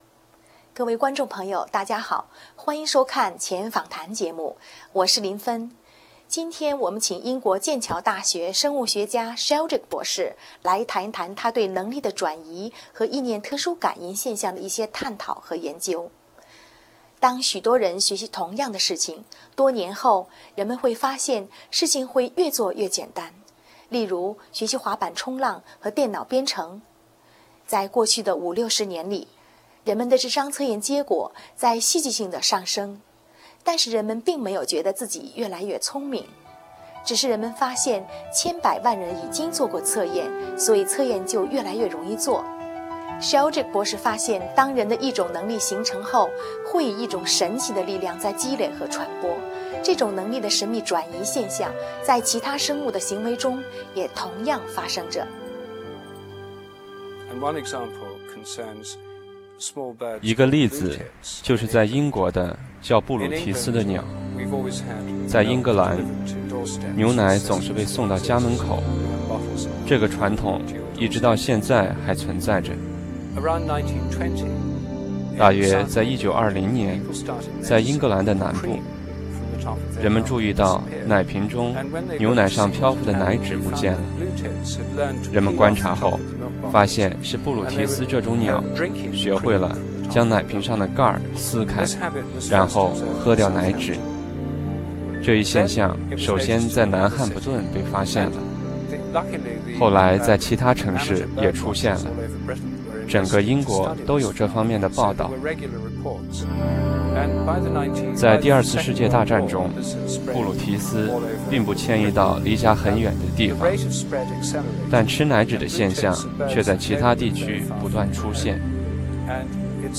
採訪英國劍橋大學生物學家Rupert Sheldrake 博士